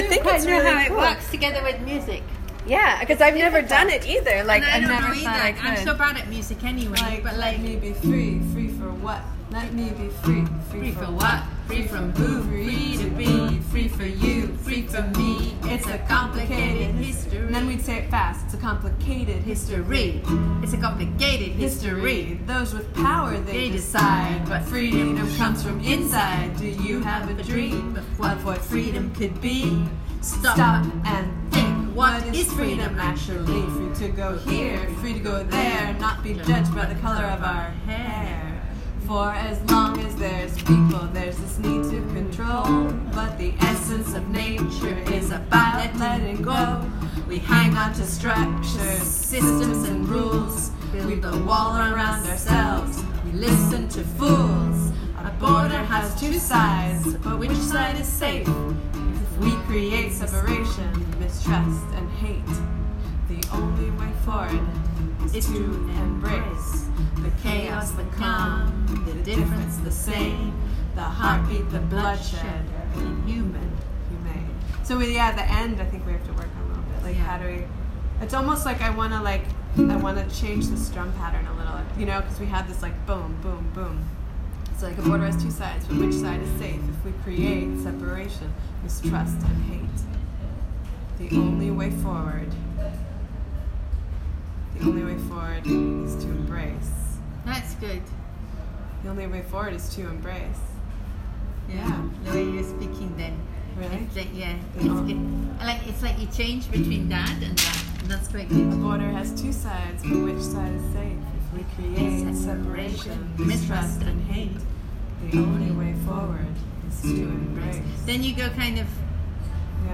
These are recordings from the center that show the process of writing the song:
Spoken word/bridge second runthrough